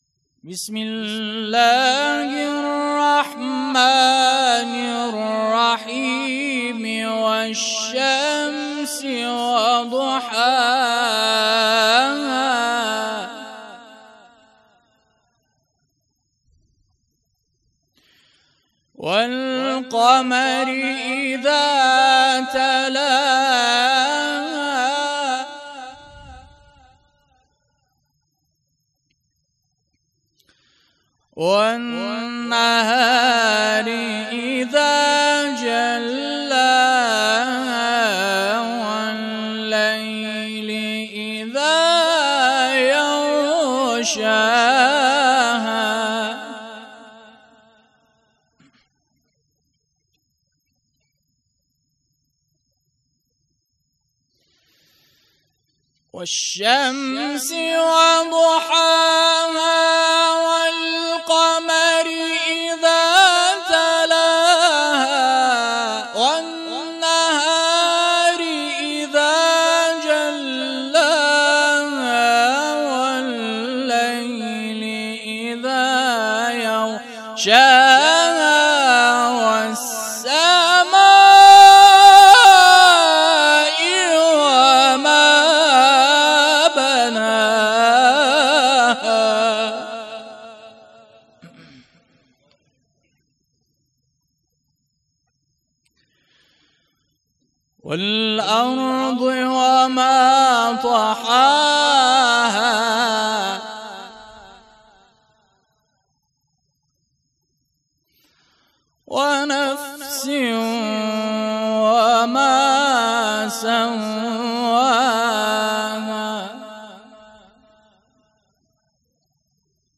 قرائت قرآن
مراسم احیای شب های قدر شب ۱۸ رمضان شهادت امیرالمومنین علیه السلام ۱۴۰۳